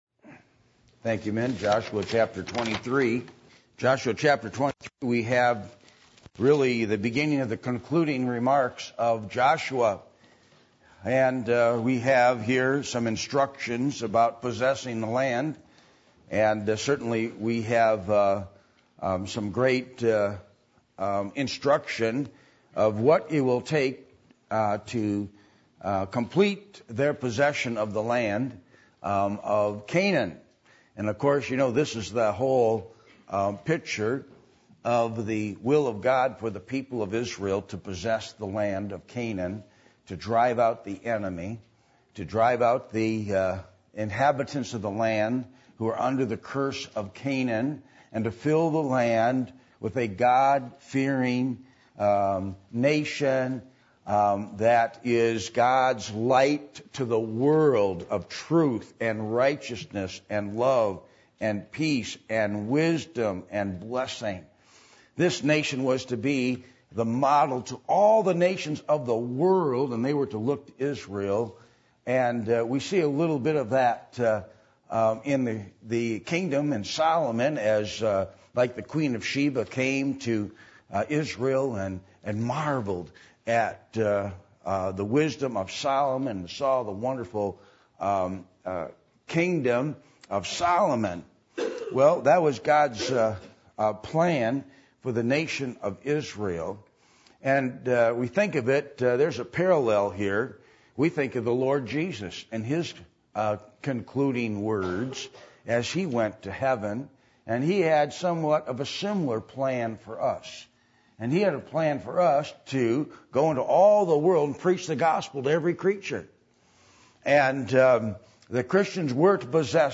Passage: Joshua 23:1-16 Service Type: Sunday Evening %todo_render% « The Eye